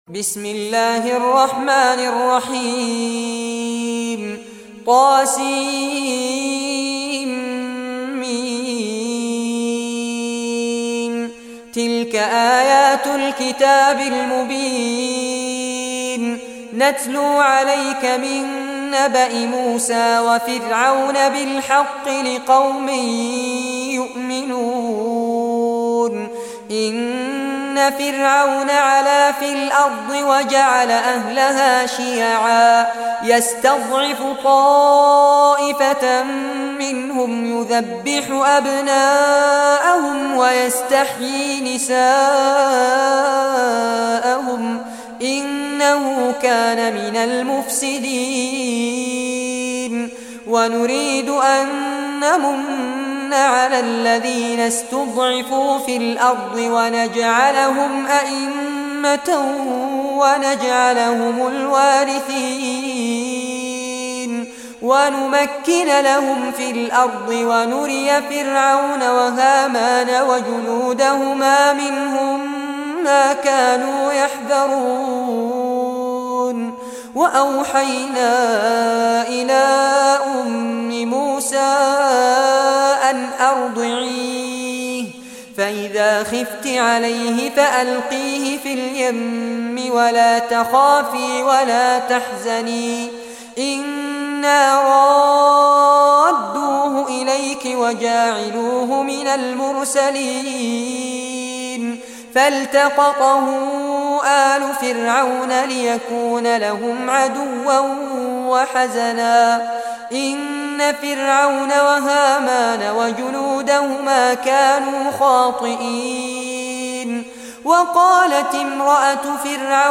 Surah Al-Qasas Recitation by Sheikh Fares Abbad
Surah Al-Qasas, listen or play online mp3 tilawat / recitation in Arabic in the beautiful voice of Sheikh Fares Abbad.